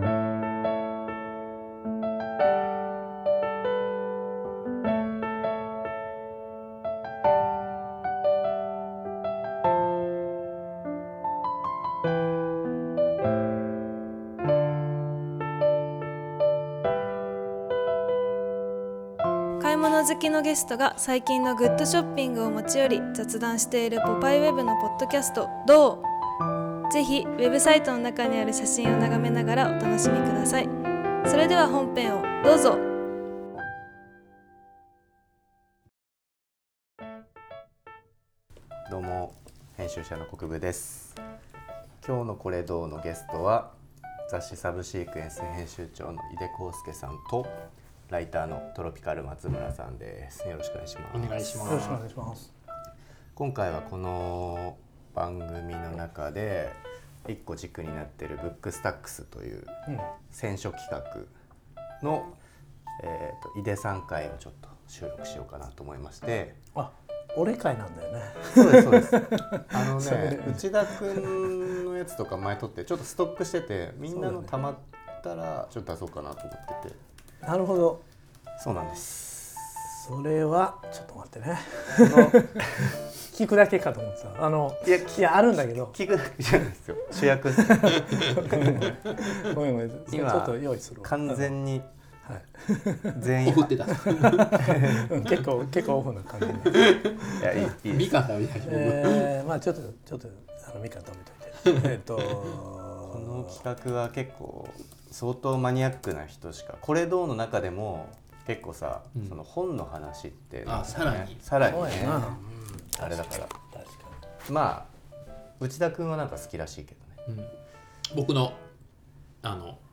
主にはPOPEYE Webのクリエイティブチームを中心に、様々なゲストがモノを持ち寄り、モノの周りにモワ〜ンと漂う想い出話やこだわりなどについて雑談中。